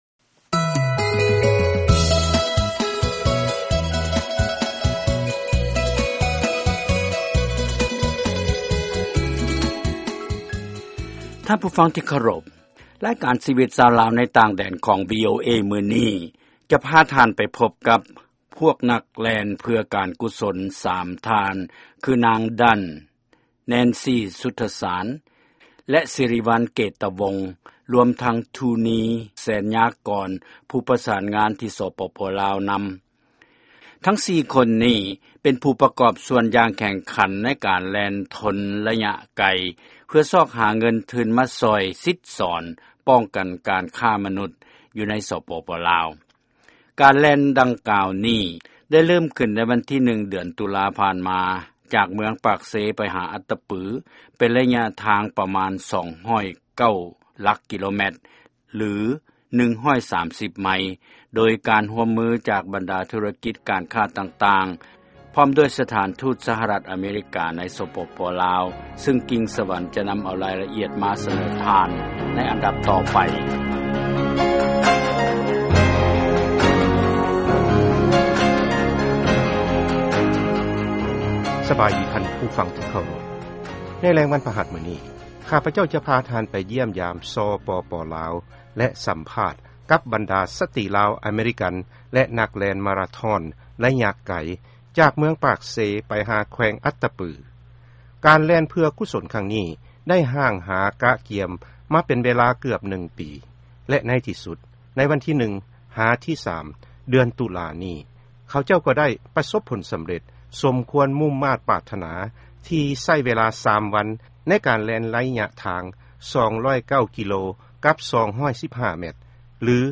ຟັງລາຍງານ ແລະການສໍາພາດນັກແລ່ນ ຊ່ວຍປ້ອງກັນການຄ້າມະນຸດ